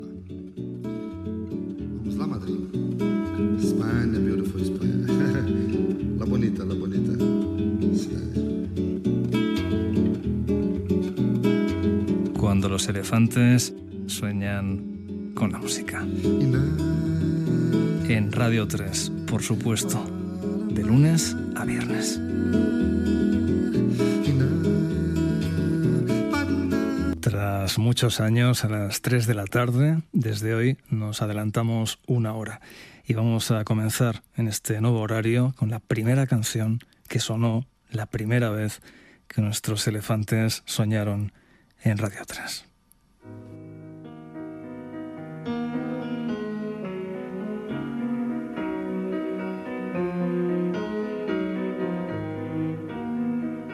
Sintonia, presentació, comentari sobre el canvi d'horari del programa, en començar la temporada 2025-2026, tema musical
Musical